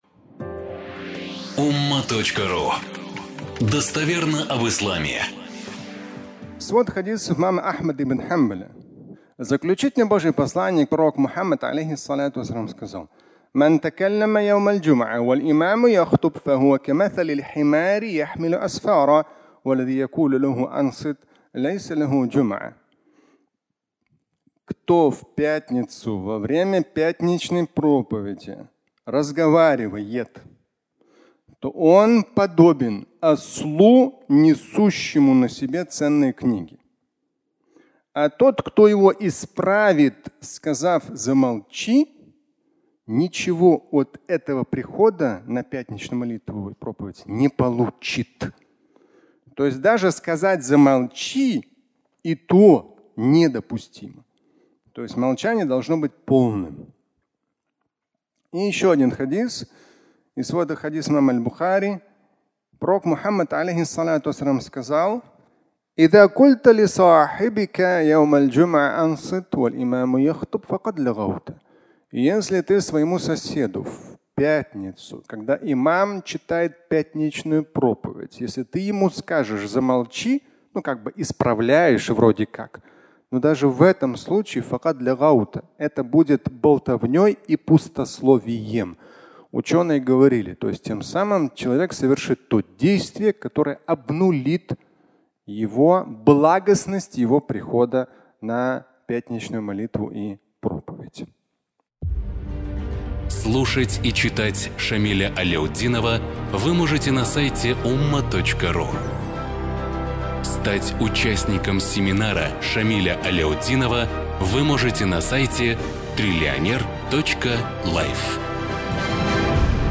Молчание в мечети (аудиолекция)
Пятничная проповедь